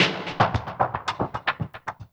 DWS SWEEP2-R.wav